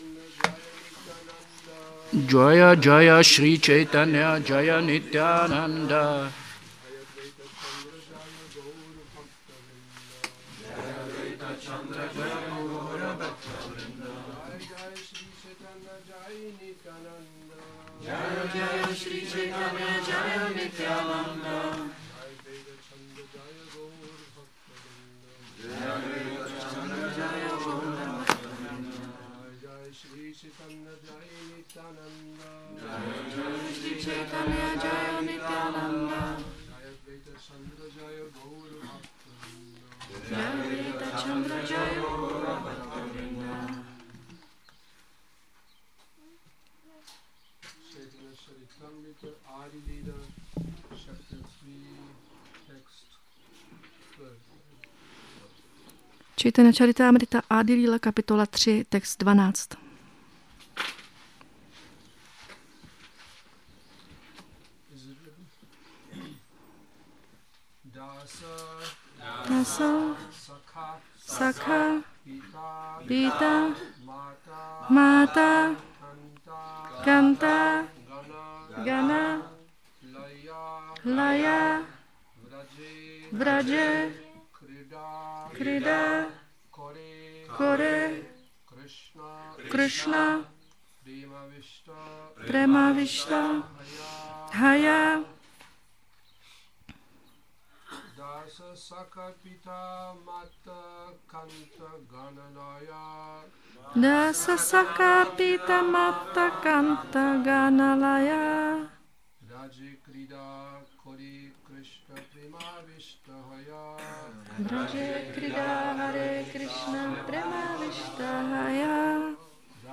Šrí Šrí Nitái Navadvípačandra mandir
Přednáška CC-ADI-3.12